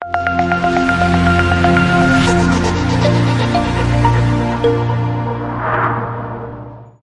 舞蹈套件 " 技术和弦
描述：直截了当，旋律优美。
标签： 小号 合成器 高科技 TECHNO 房子 和弦 旋律优美 声音
声道立体声